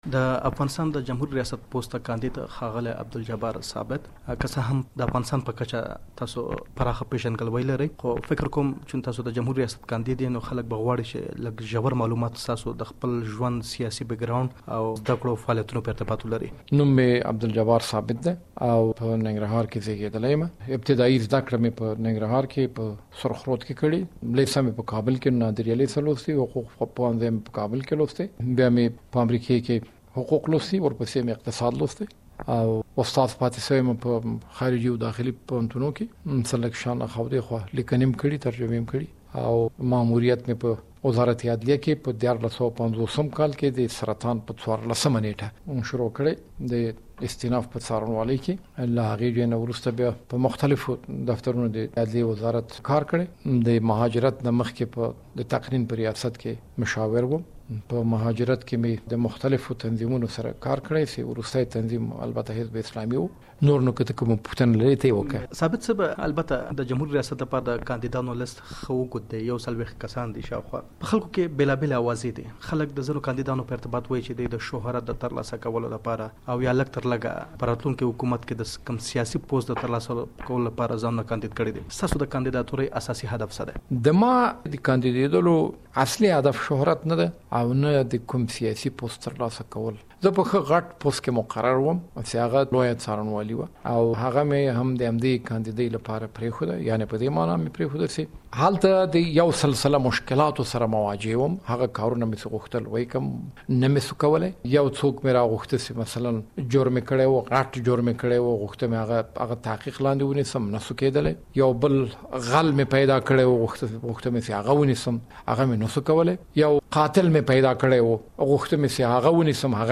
د ولسمشرۍ د څوکۍ له کاندیدعبدالجبار ثابت سره ځانګړې مرکه واورﺉ